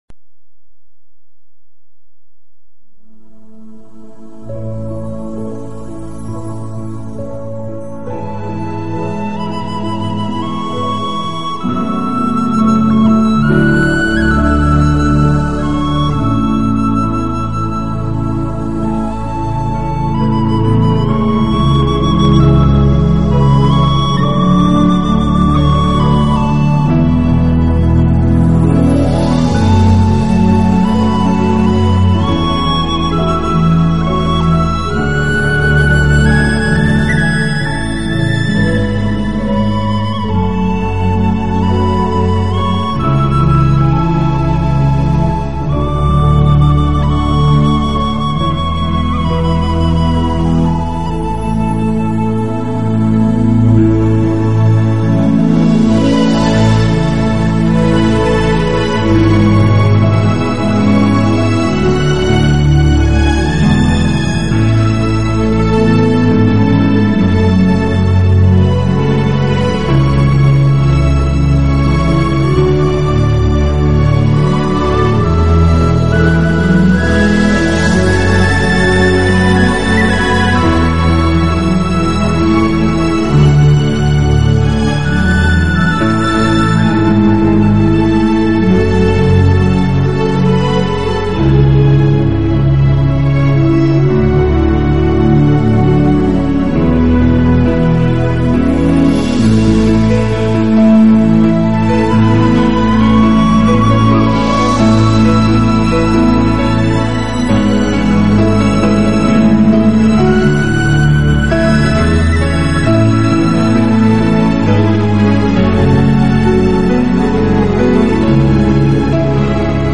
【新世纪钢琴】
钢琴及电子合成器演奏家。